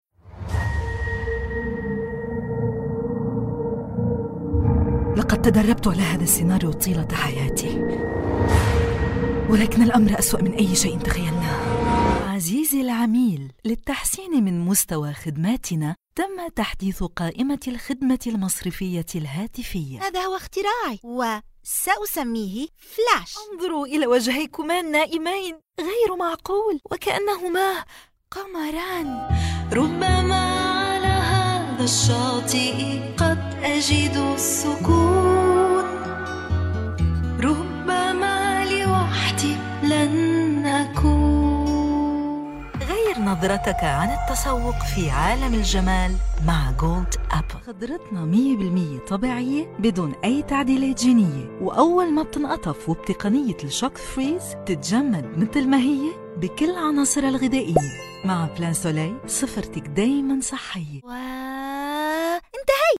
Voice Artists - Female